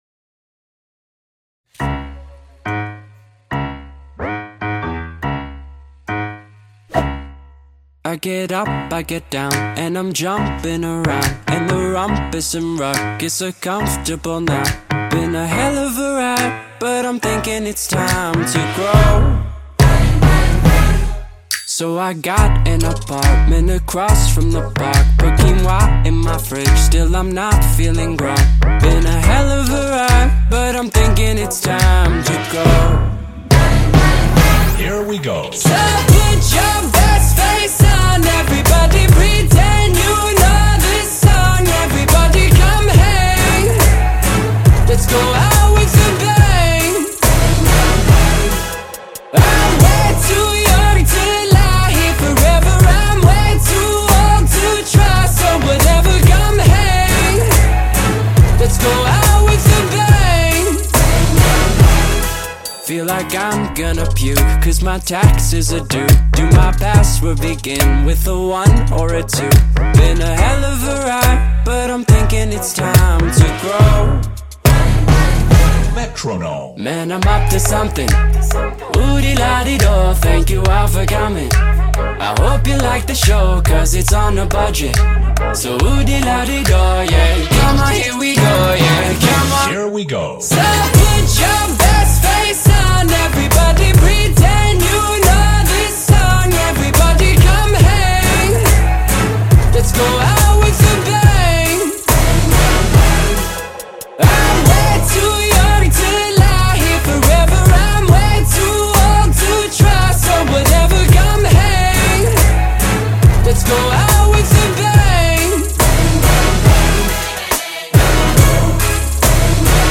BPM140-140
Audio QualityCut From Video